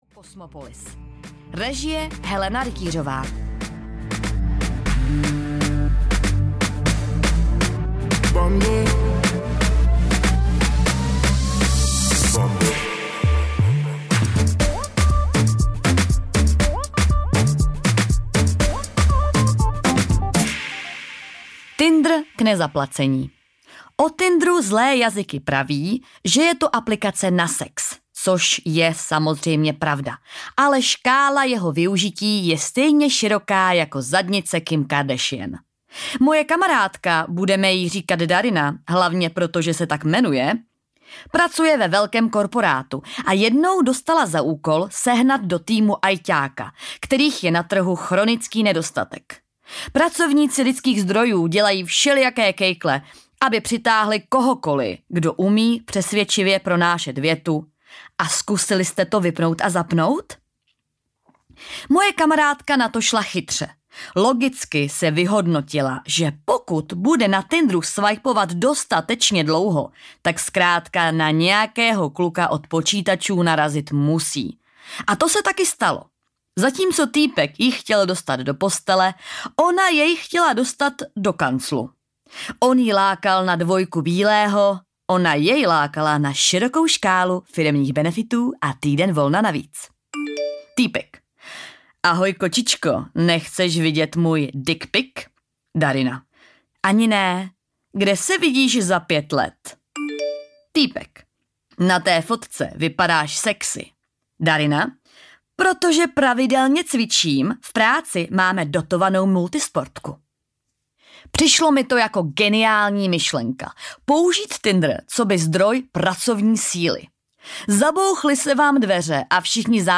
Historky z Tinderu audiokniha
Ukázka z knihy